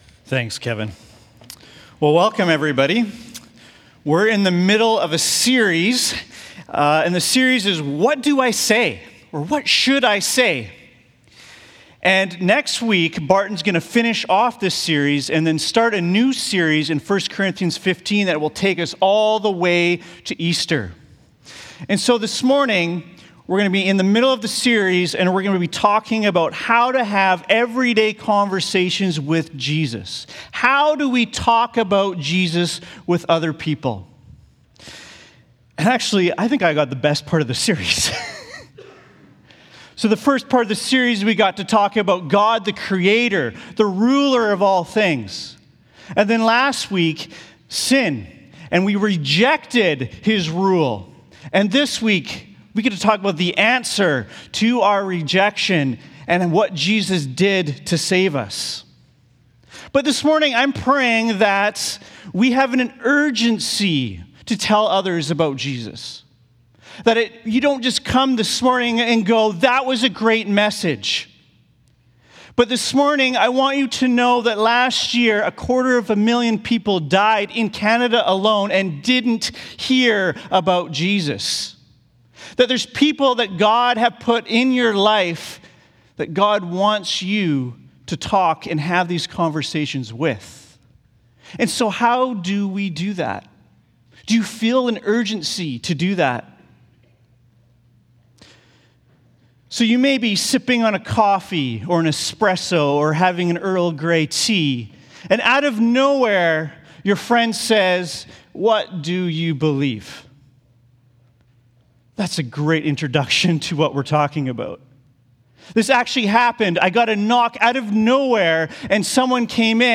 Sermons | Central Baptist Church